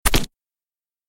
دانلود آهنگ تصادف 32 از افکت صوتی حمل و نقل
جلوه های صوتی
دانلود صدای تصادف 32 از ساعد نیوز با لینک مستقیم و کیفیت بالا